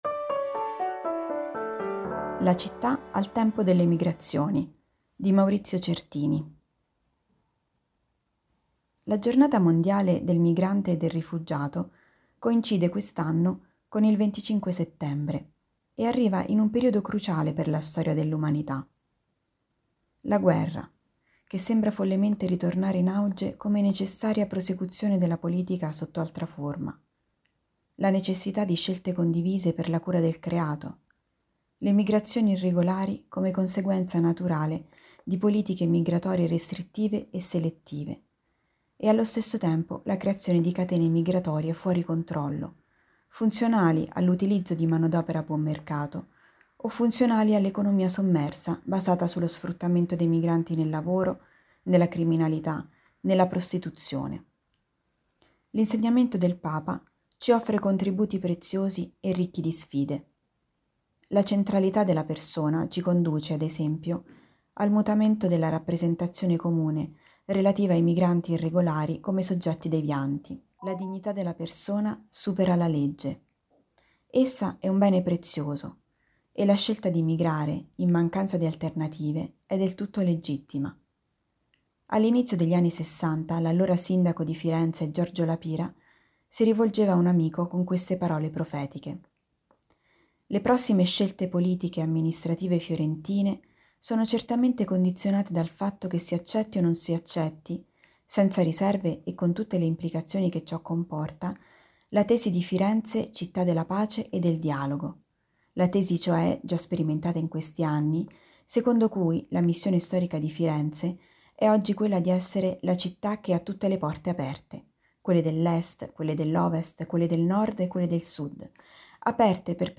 Al microfono, i nostri redattori e nostri collaboratori.